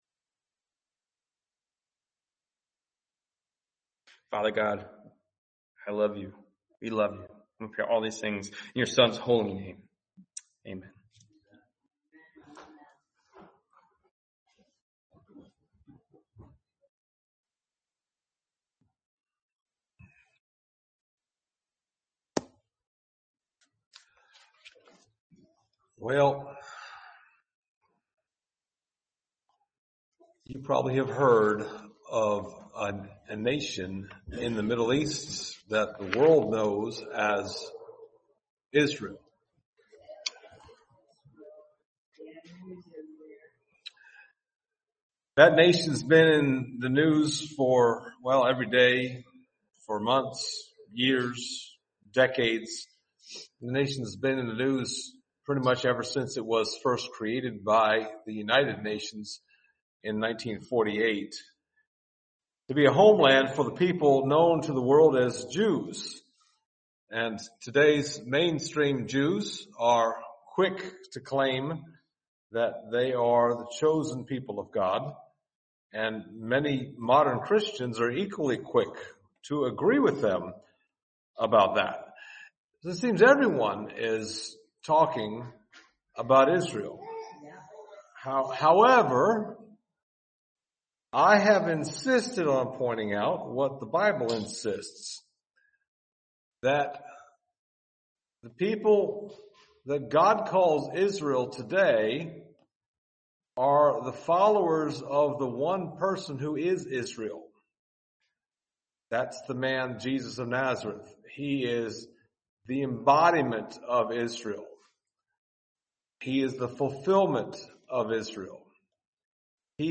Passage: Acts 10:42-48 Service Type: Sunday Morning